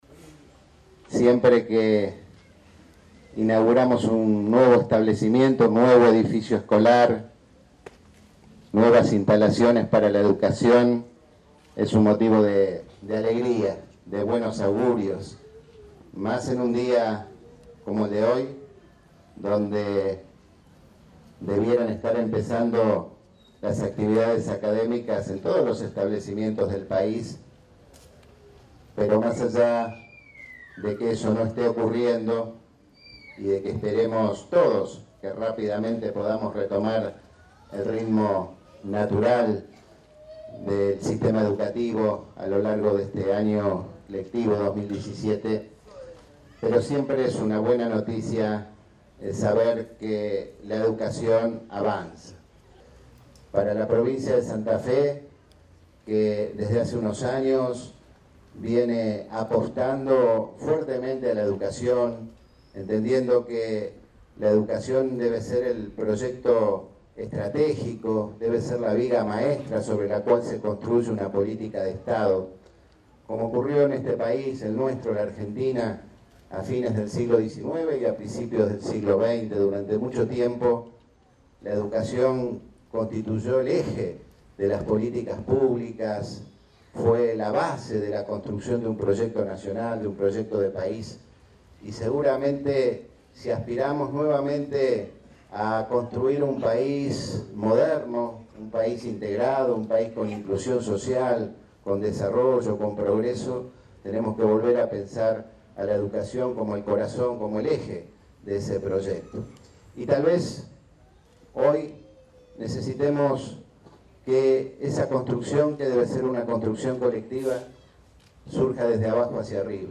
“Una escuela especial nos muestra que la educación es un derecho para todos y debemos hacerlo concreto”, dijo el gobernador.
El gobernador Miguel Lifschitz presidió este lunes el acto de inauguración de las obras de ampliación del edifico de la Escuela de Educación Especial N° 1429 “Dra. Sara Faisal”, en la ciudad de Santa Fe.